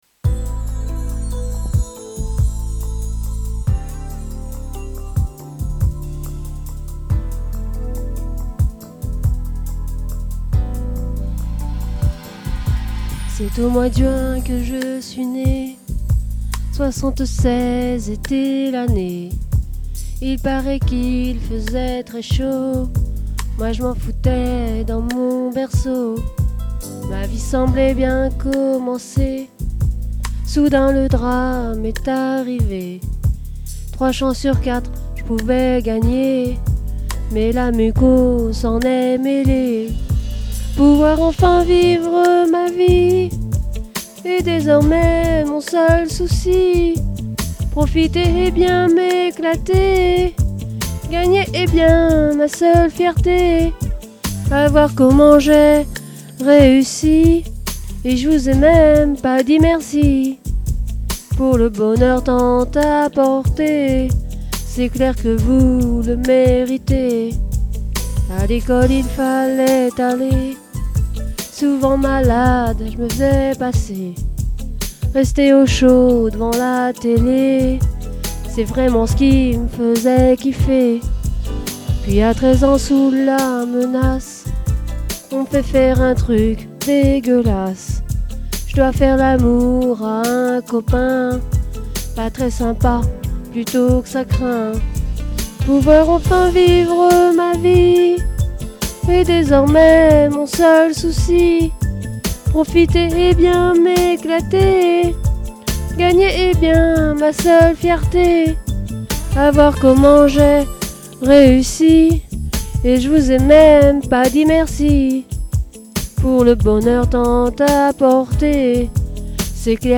Je chante parce que j'aime ça, il me manque des cours de chant pour faire quelque chose de juste et de professionnel.